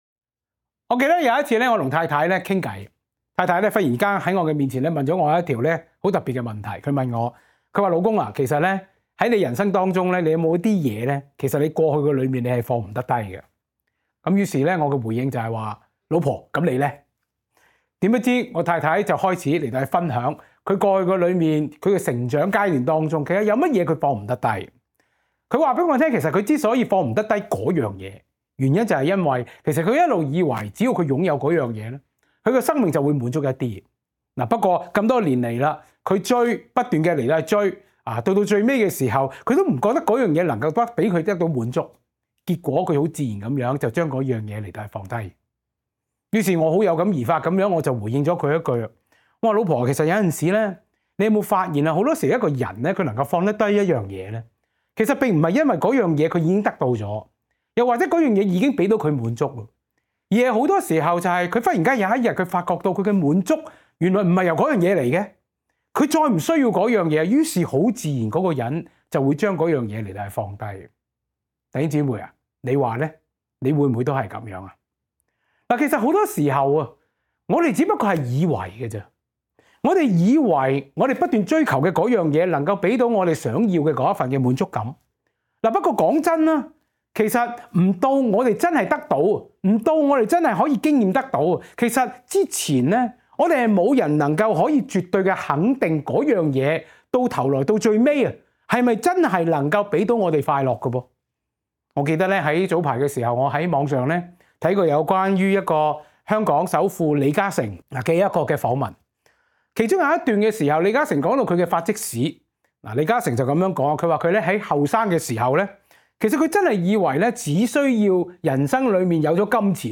下載 下載 歌鄰基督教會 本週報告事項 (Jan 4, 2025) 假的真不了 Current 講道 假的真不了 (2) 滿足從何來？